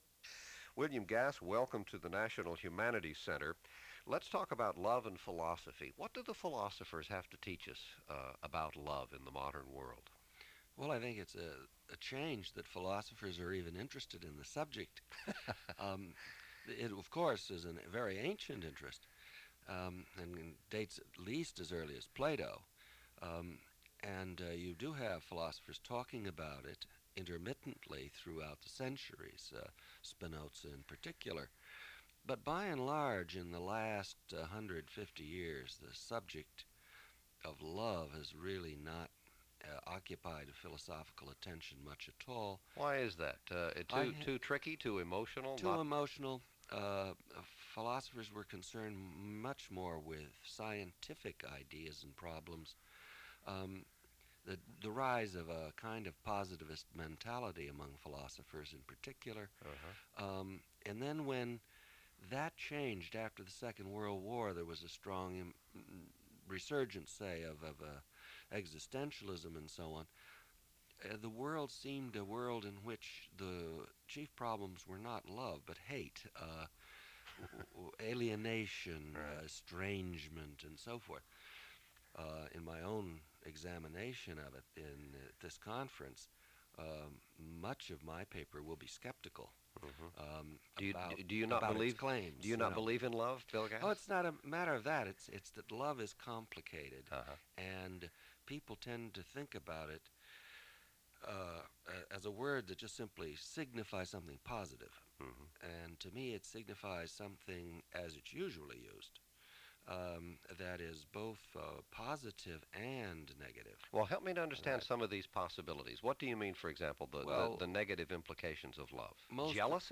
"Soundings" NHC Interview · William H. Gass: The Soul Inside the Sentence · Digital Exhibits
79df470399a995f4f39fedfeff85af5ff49e2b5f.mp3 Title "Soundings" NHC Interview Description On the occasion of participating in a 1988 National Humanities Center conference on love, marriage, gender and sexuality, Gass spoke to an interviewer about love and philosophy in the modern world.